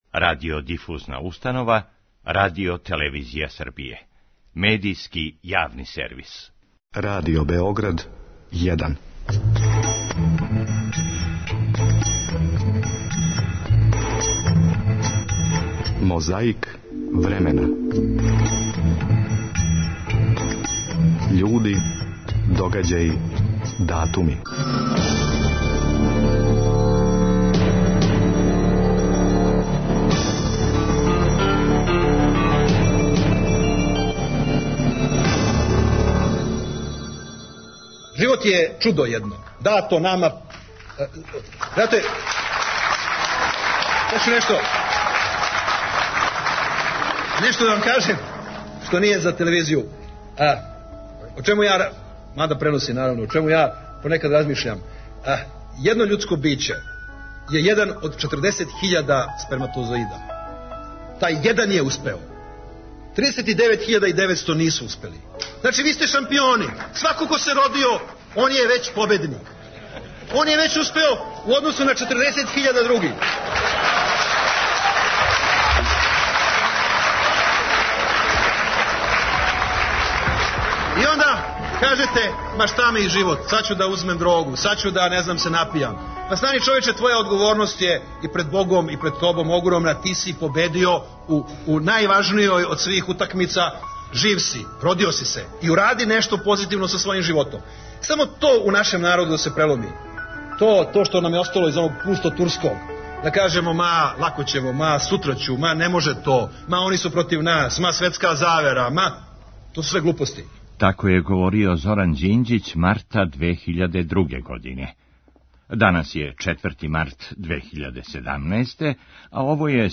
У циљу борбе против пилећег памћења подсећамо на говор Зорана Ђинђића у Јагодини марта 2002. године када је владина делегација била на турнеји под слоганом „Србија на добром путу“.
Хумористичка емисија Радио Београда „Весело вече“ први пут се нашла у етру 6. марта 1949. године. Следећих нешто више од пола века недељно вече у југословенским домовима било је обојено смехом и гласовима: Мије Алексића, Миодрага Петровића Чкаље, Мире Ступице, Бранке Веселиновић и других великана нашег глумишта…